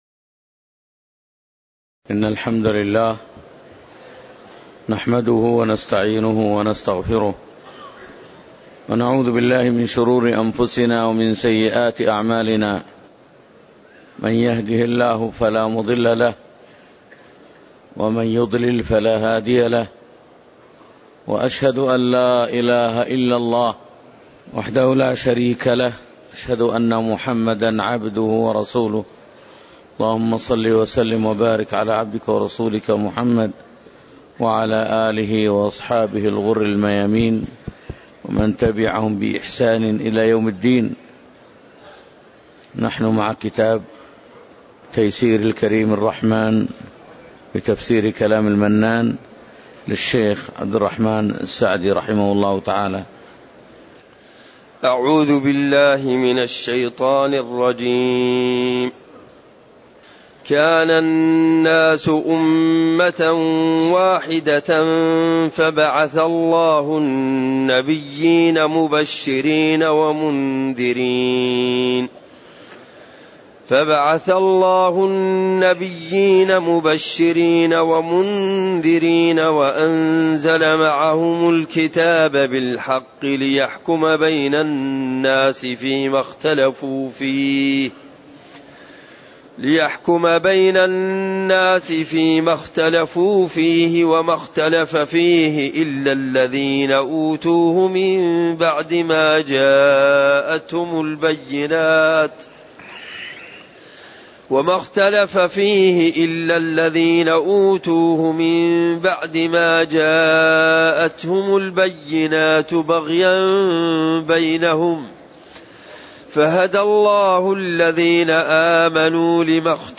تاريخ النشر ٢ محرم ١٤٣٩ هـ المكان: المسجد النبوي الشيخ